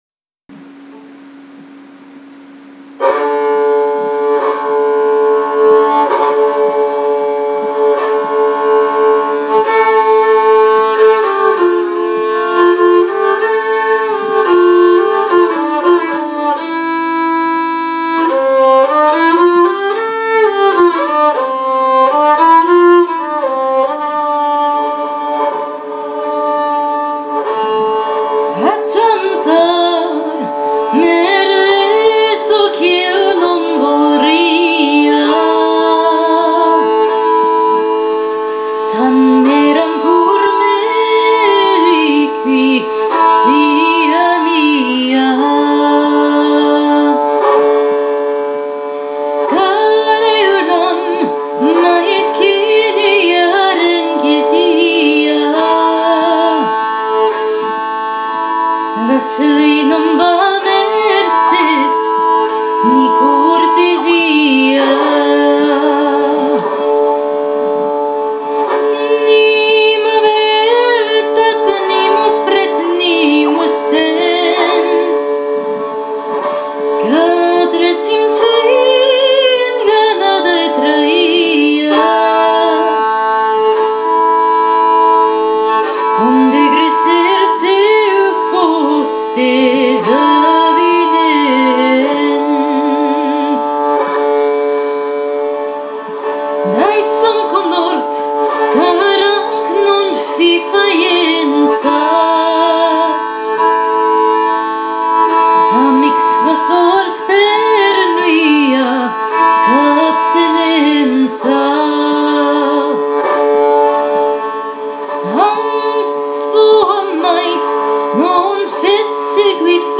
Through Vielle and Song: Exploring Medieval Music and Trobairitz Canso | Performing Trobar